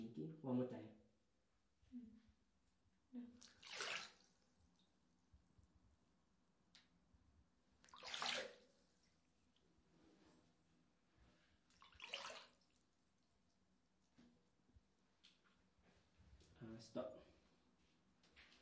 环境回声
描述：环境回声 这种声音需要操作员使用三角波和大量混响的组合。我通过使用两个不同的八度音程来分层声音，以覆盖听起来最好的大多数频率范围并填充声音效果中的任何间隙。这种声音的灵感来自场景转换和宽镜头，其中可能缺少其他声音，因此这可以释放出一个空间，可以获得充足的延音和混响。操作员的三角波给了它一个高音平静流动的声音。例如正弦波的其他选项听起来对于这种类型的声音来说是刺耳和尖锐的。
标签： 环境 设计 操作 声音
声道立体声